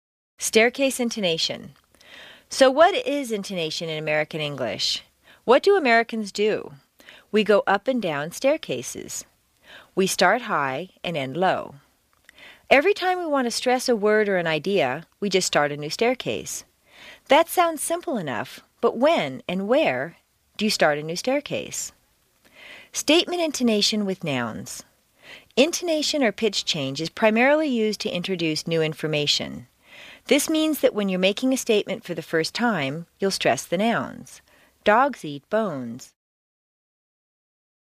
美式英语正音训练第52期:阶梯状语调 听力文件下载—在线英语听力室